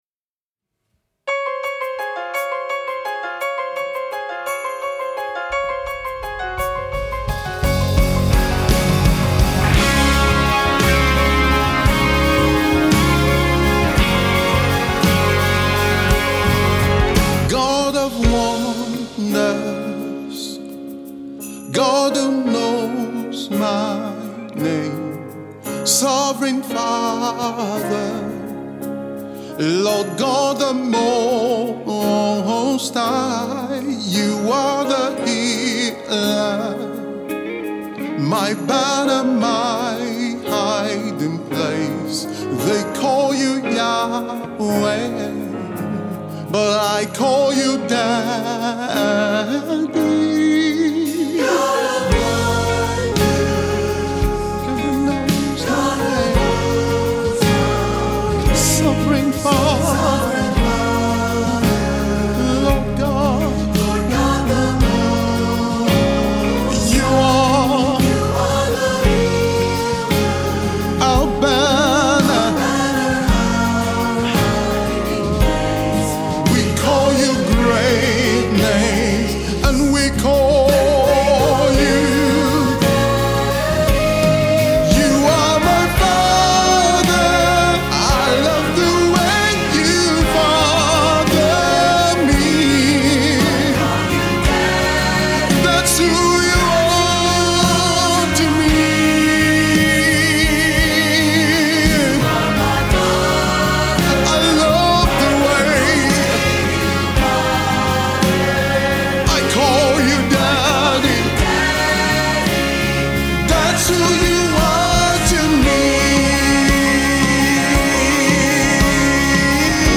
gospel
This compelling canticle